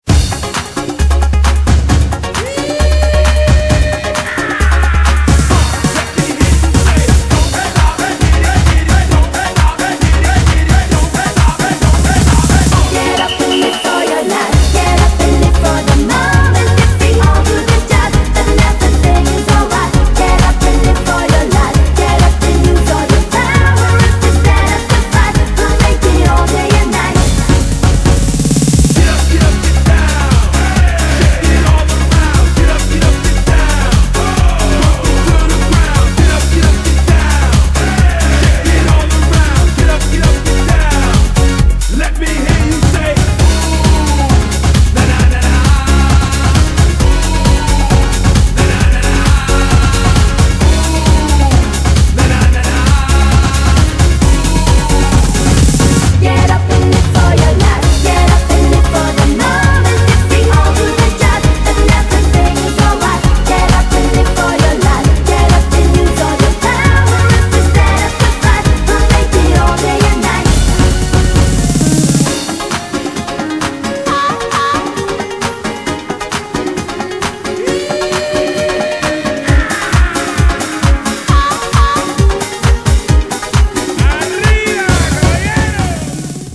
BPM133
Audio QualityPerfect (Low Quality)